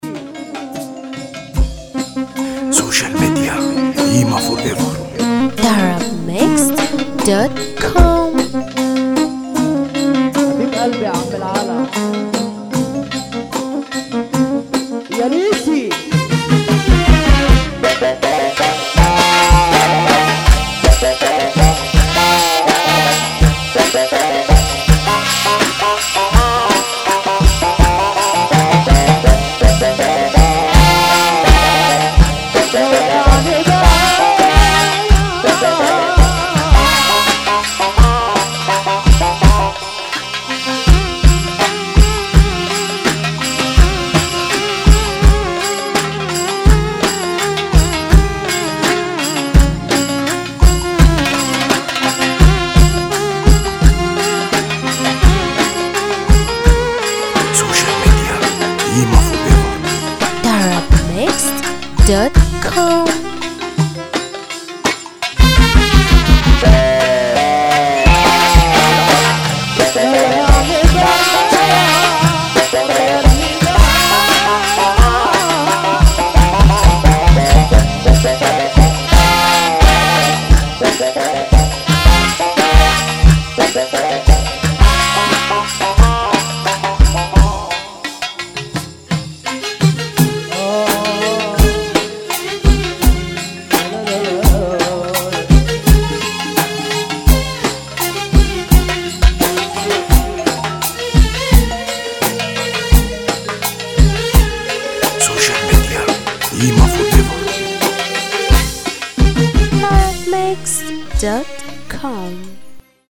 مزمار